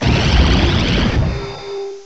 cry_not_naganadel.aif